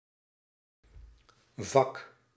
Ääntäminen
IPA: /vɑk/